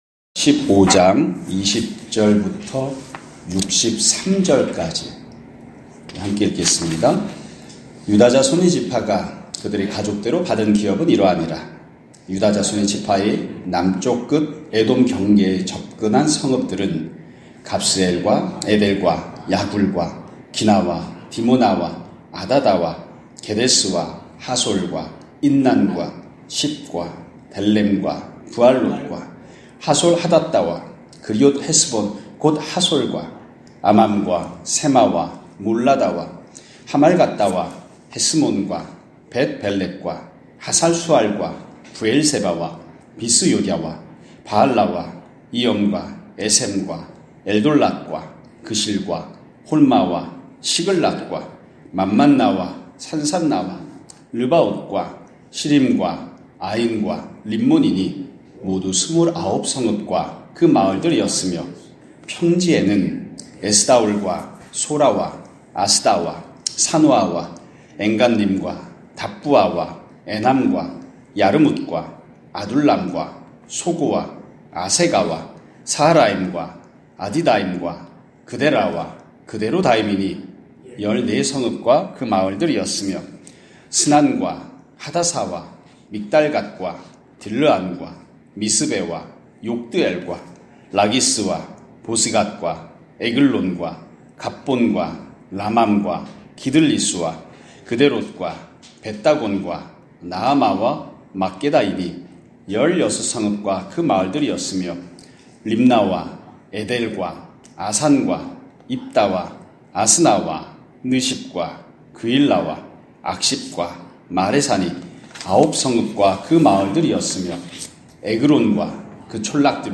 2024년 12월 9일(월요일) <아침예배> 설교입니다.